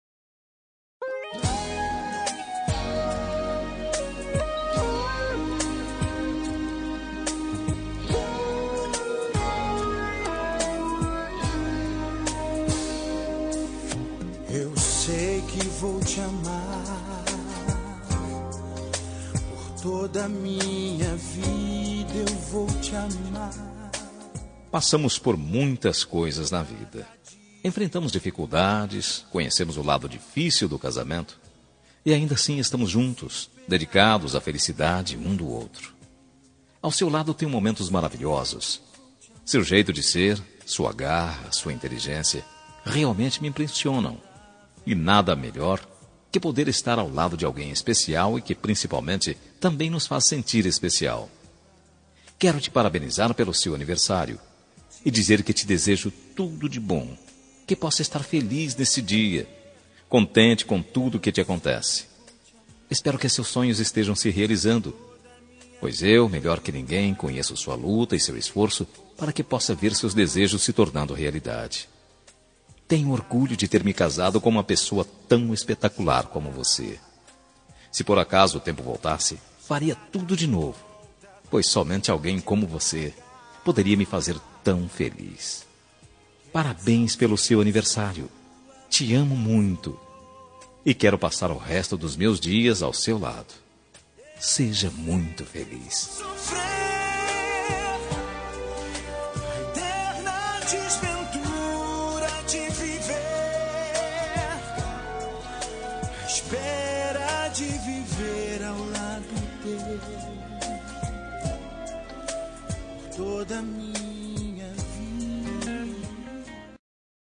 Telemensagem Aniversário de Esposa – Voz Masculina – Cód: 1124